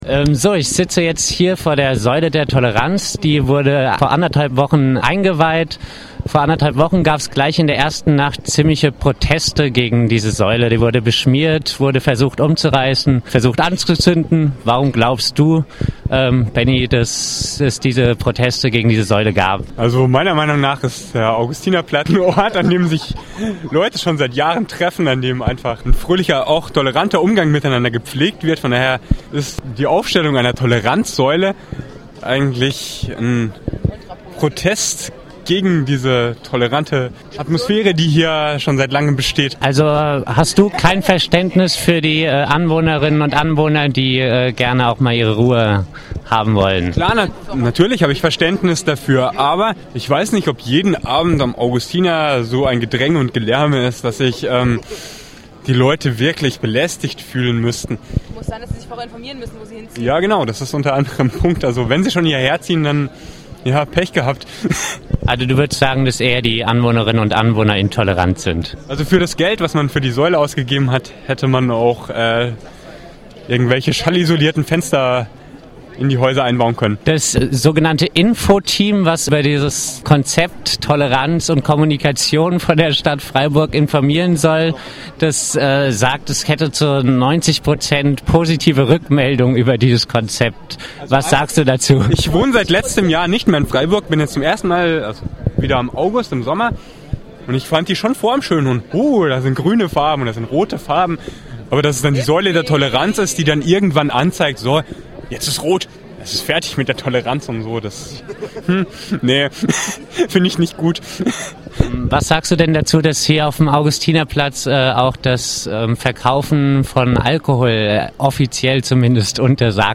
O-Töne zur Säule der Toleranz
Samstagabend auf dem Freiburger Augustinerplatz: Wir haben Stimmen zur umstrittenen "Säule der Toleranz" eingefangen, die verdeutlichen, dass sogenannte Kommunikations- und Toleranz Konzept der Stadt bei den Nutzerinnen und Nutzern nicht gut ankommt...